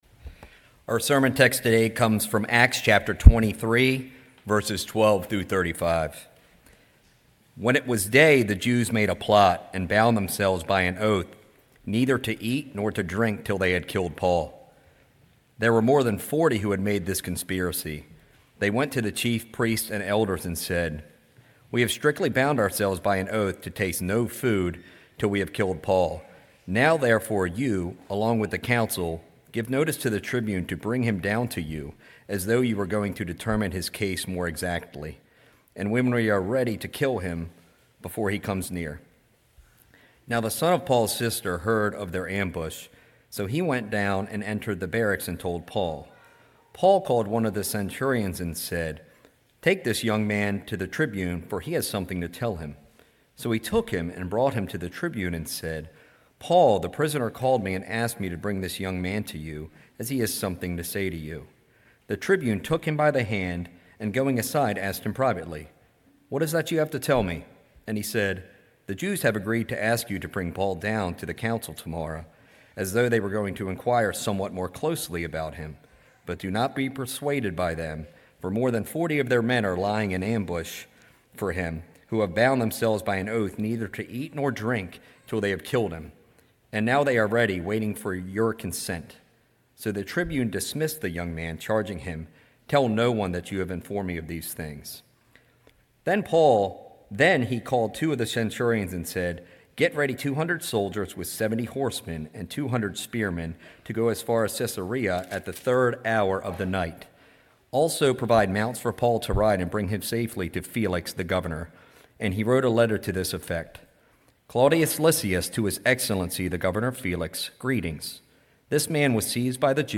sermon9.28.25.mp3